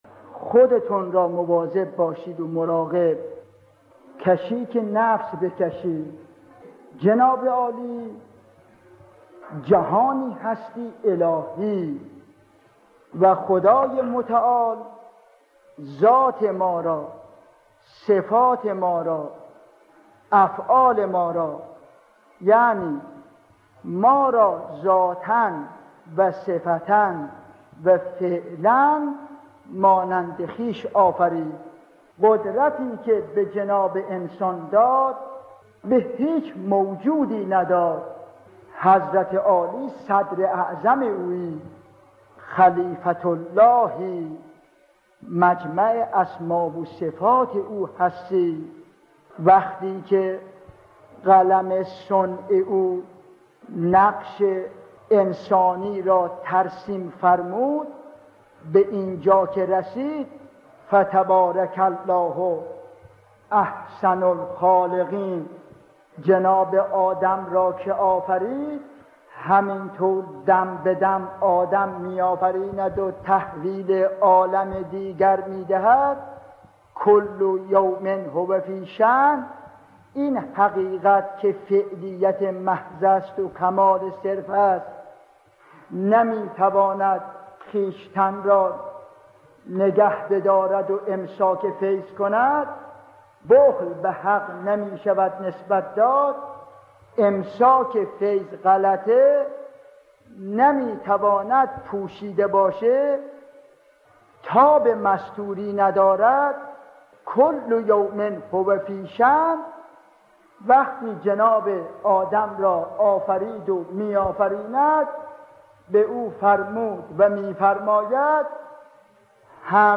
مجموعه پادکست «روایت بندگی» با کلام اساتید بنام اخلاق به کوشش ایکنا گردآوری و تهیه شده است که بیست وچهارمین قسمت این مجموعه با کلام آیت‌الله علامه حسن حسن‌زاده آملی(ره) با عنوان «مراقبه نفس» تقدیم مخاطبان گرامی ایکنا می‌شود.